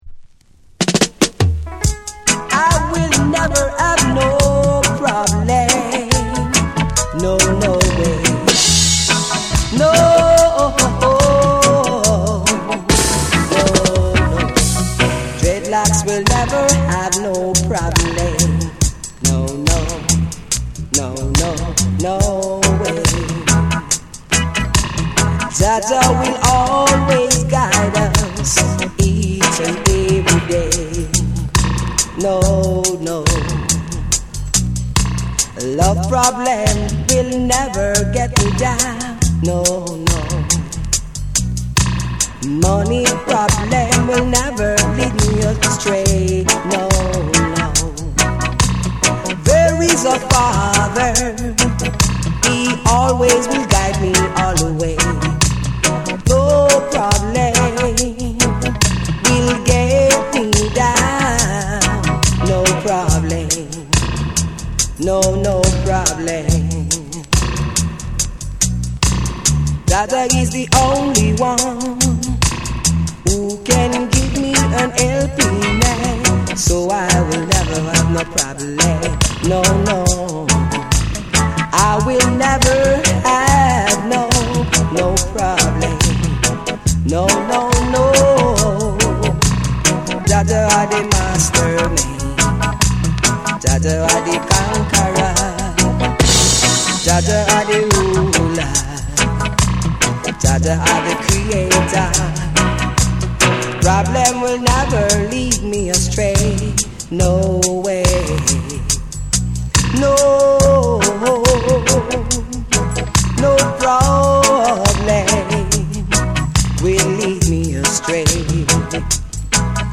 REGGAE & DUB